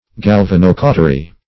Search Result for " galvanocautery" : The Collaborative International Dictionary of English v.0.48: Galvanocautery \Gal*van`o*cau"ter*y\, n. (Med.)
galvanocautery.mp3